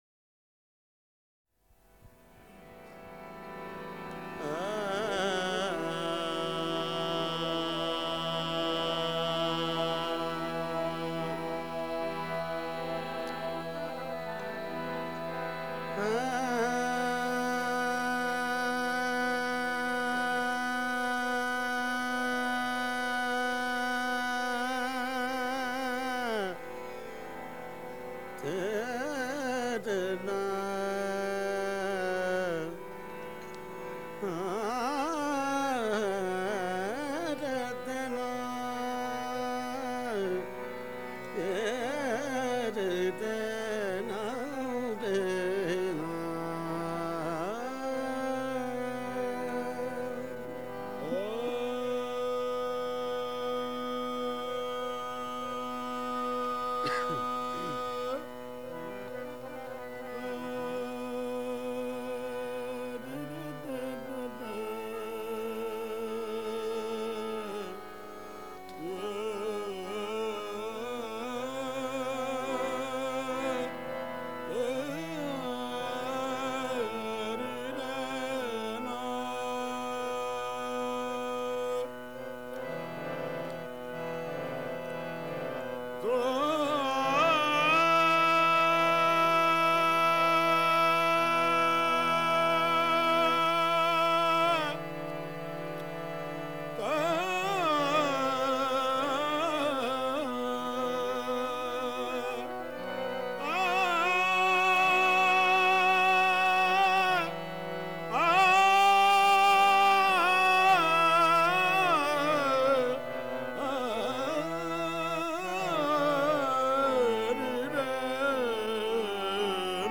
Qawwali Songs